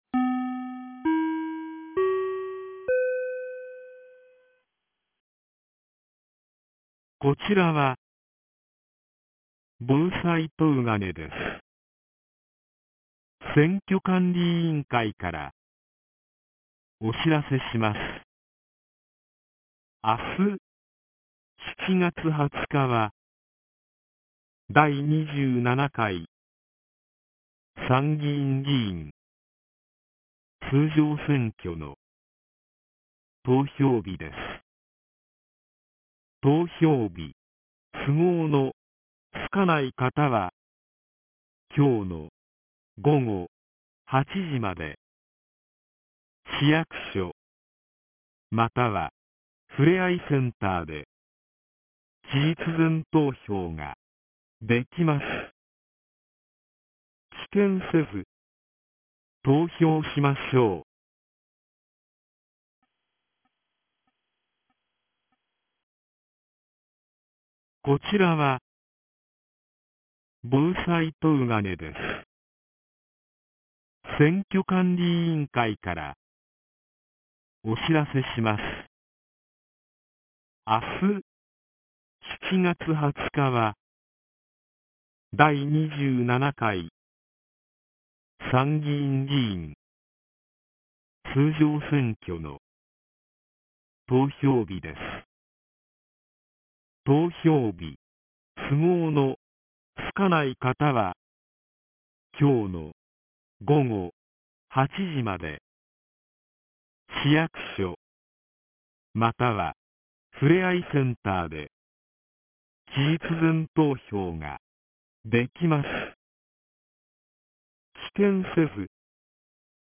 2025年07月19日 09時02分に、東金市より防災行政無線の放送を行いました。